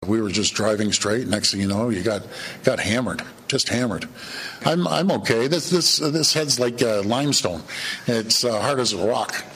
Ford, at a press conference following the collision, confirmed he was fine.